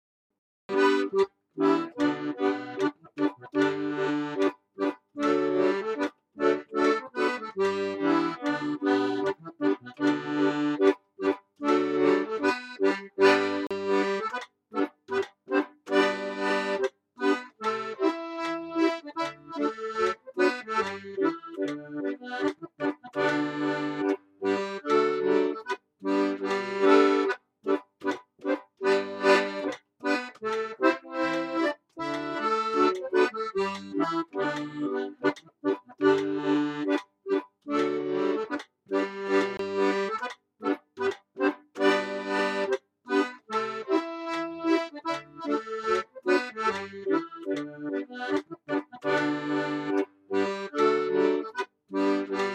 The following videos are based on the tune of the “Rose Tree” and the practice audio tracks are available here for you to dance to.